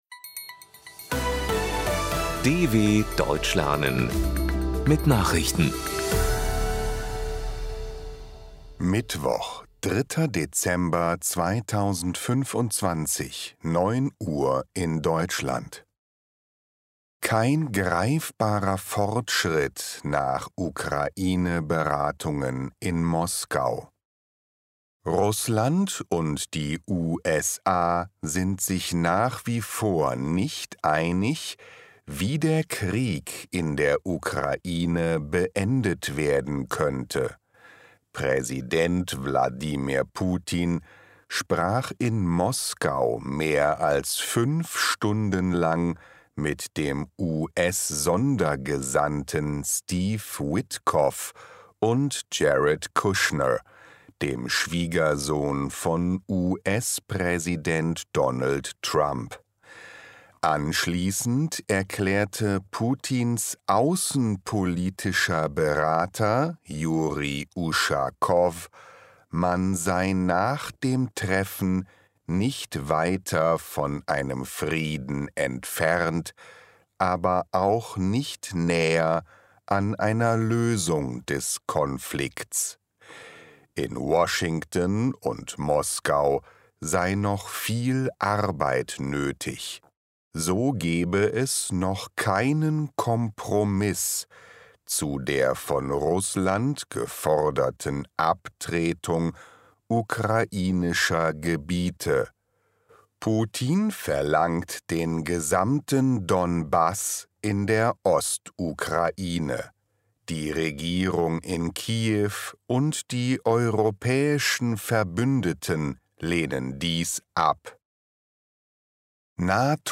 03.12.2025 – Langsam Gesprochene Nachrichten
Trainiere dein Hörverstehen mit den Nachrichten der DW von Mittwoch – als Text und als verständlich gesprochene Audio-Datei.